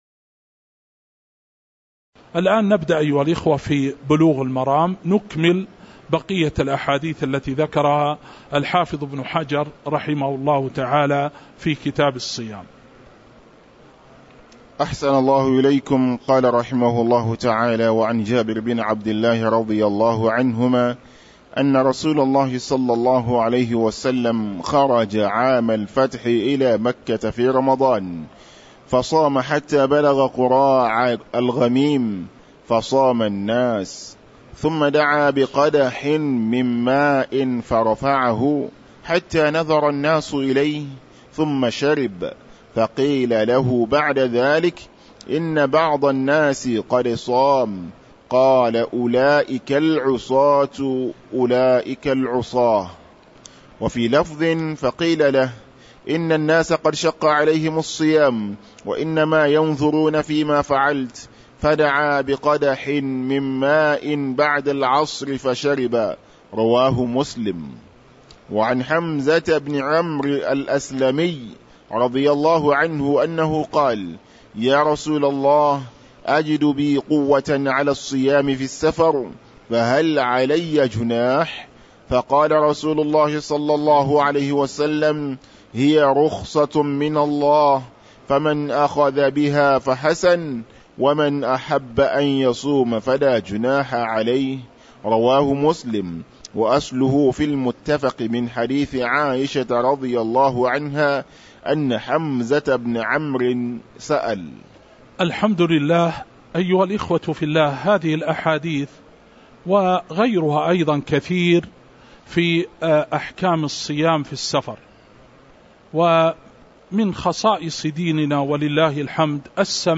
تاريخ النشر ٢٧ رمضان ١٤٤٦ هـ المكان: المسجد النبوي الشيخ